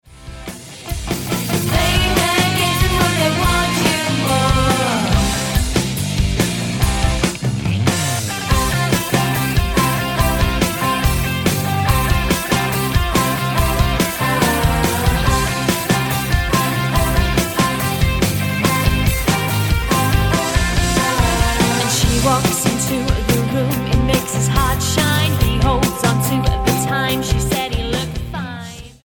electro pop duo